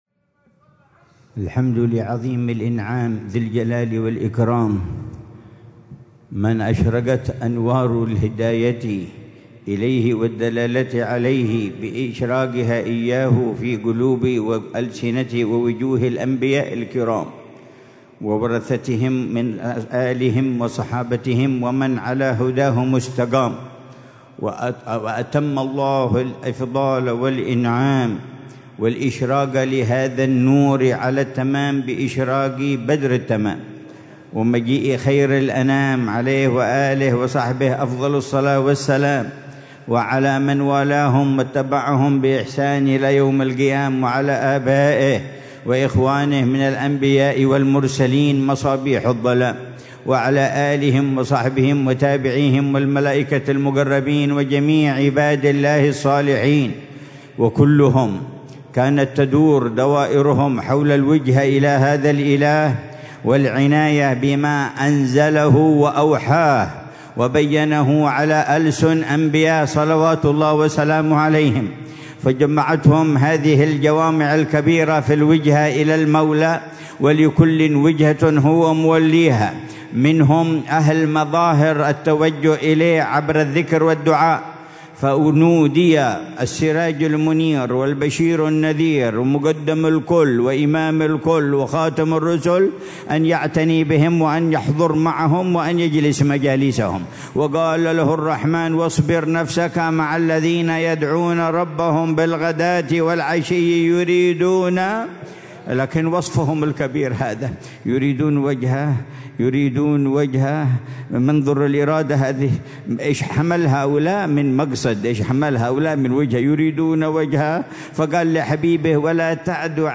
محاضرة العلامة الحبيب عمر بن محمد بن حفيظ في حفل اختتام الدورة التعليمية الحادية والثلاثين بدار المصطفى بتريم للدراسات الإسلامية، ليلة السبت 15 صفر الخير 1447هـ بعنوان: